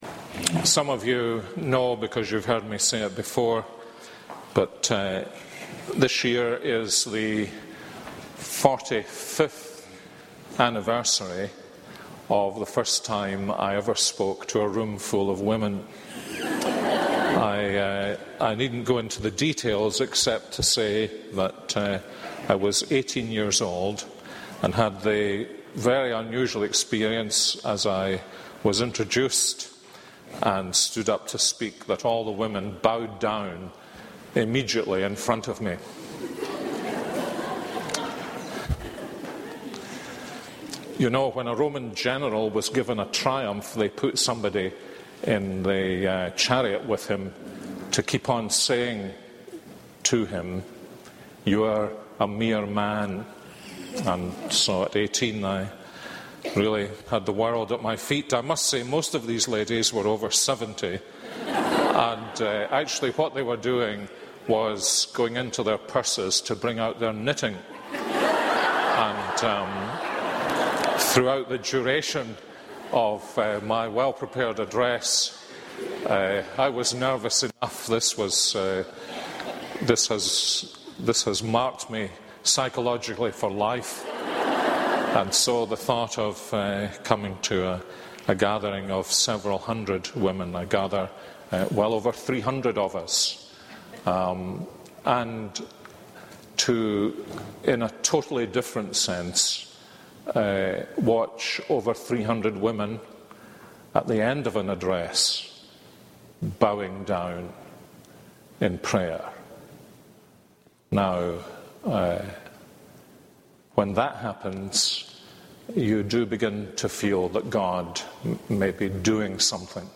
This is a sermon on Psalm 85:1-13.